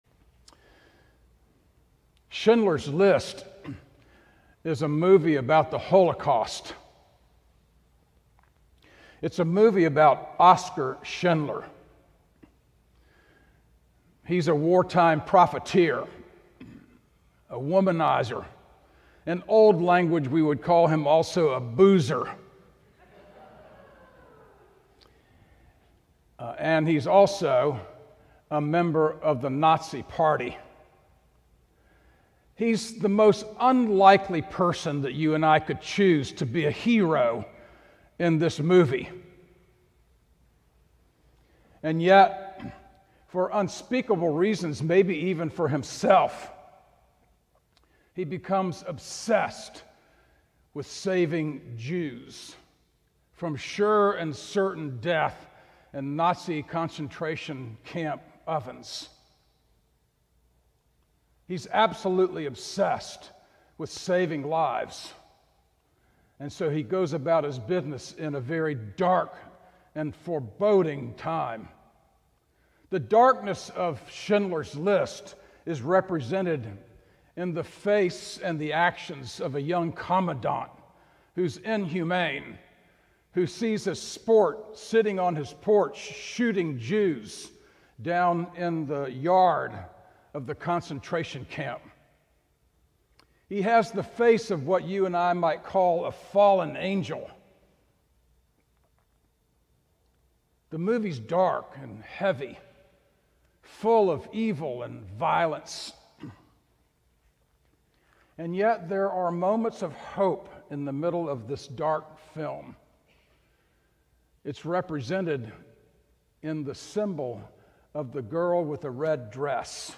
Sermons from St. John's Cathedral Three Assurances at Easter Play Episode Pause Episode Mute/Unmute Episode Rewind 10 Seconds 1x Fast Forward 30 seconds 00:00 / 00:21:21 Subscribe Share Apple Podcasts RSS Feed Share Link Embed